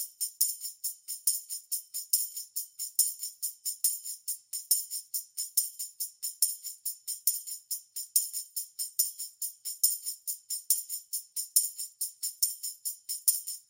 铃鼓循环 " 铃鼓循环 70bpm
描述：自录Tambourine Loop 70 BPM或140 BPM打击乐器
Tag: 循环 铃鼓 70 仪器 BPM 打击乐器